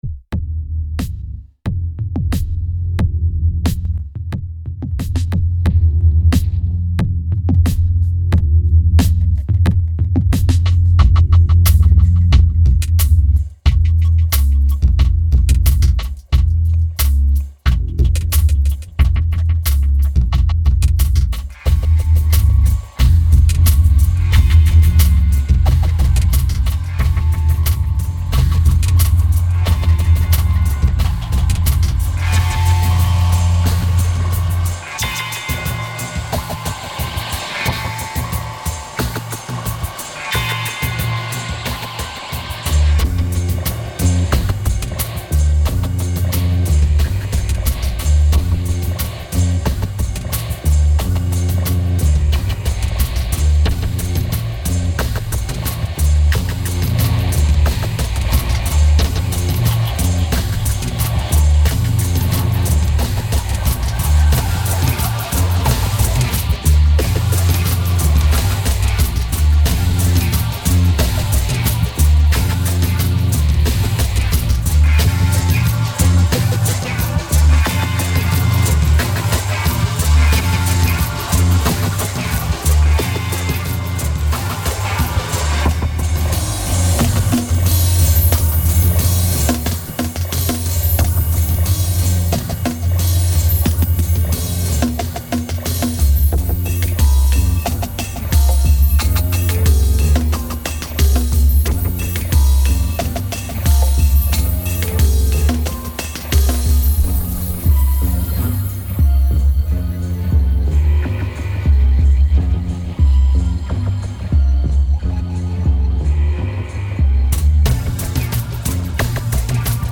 2184📈 - -28%🤔 - 90BPM🔊 - 2009-03-13📅 - -439🌟